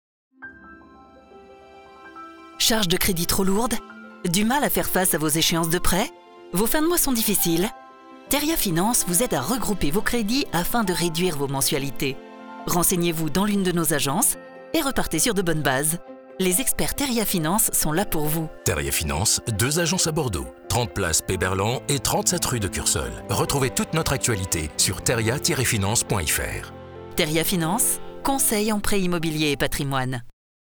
Découvrez notre nouvelle campagne radio sur Wit FM !